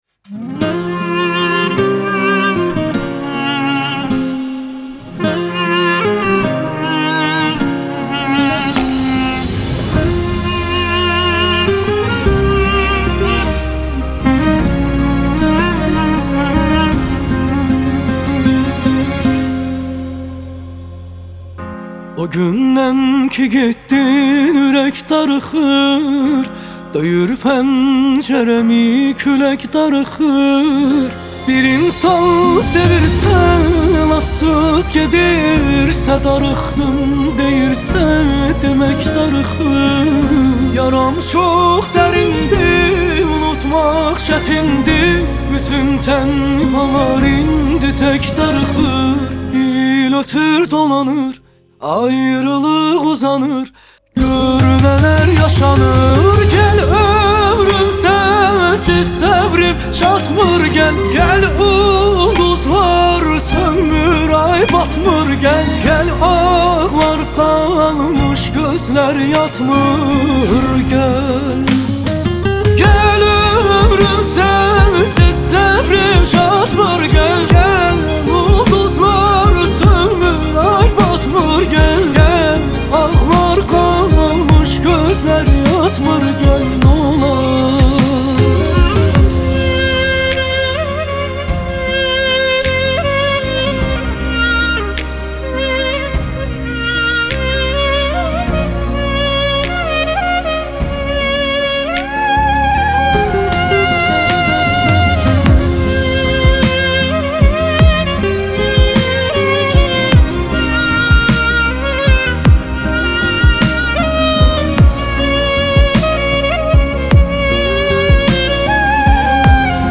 آهنگ آذربایجانی آهنگ غمگین آذربایجانی آهنگ هیت آذربایجانی